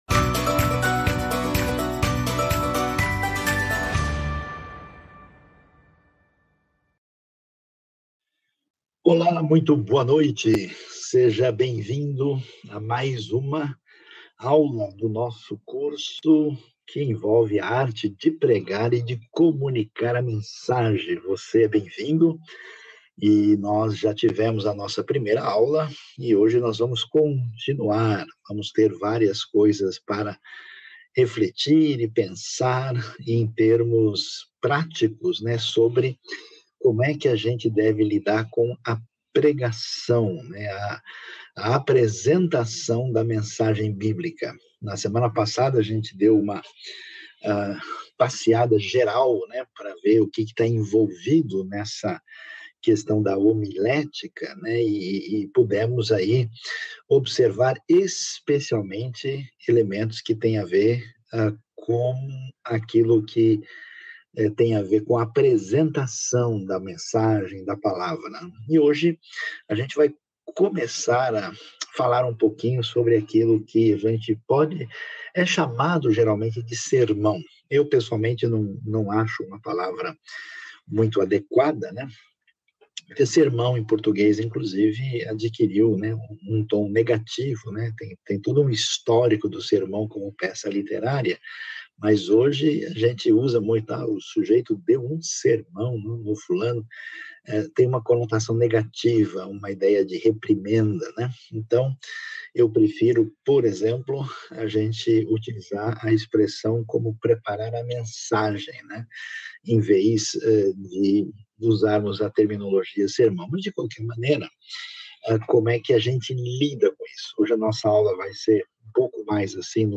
Os tipos de pregação (Sermão)